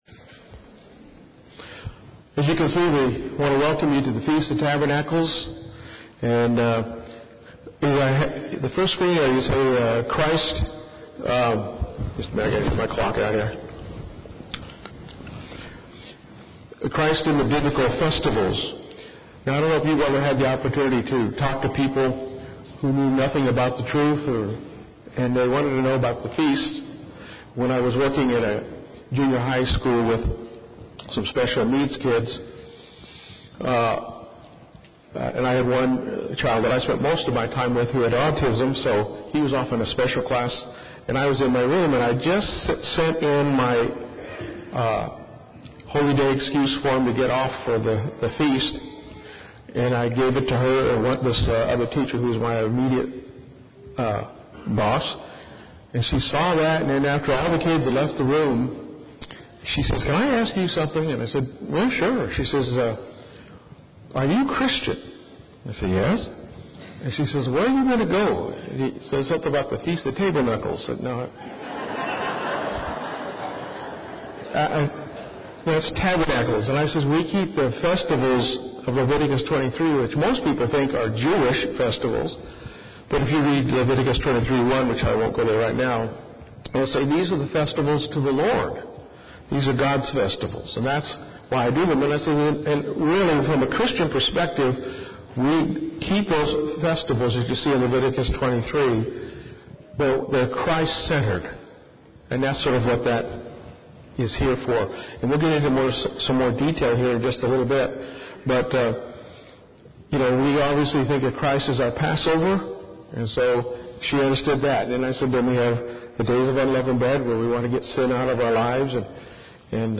This sermon was given at the Galveston, Texas 2015 Feast site.